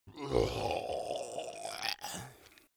Burp Monster Zombie groan moan
Burp groan moan moaning monster Monster undead zombie sound effect free sound royalty free Memes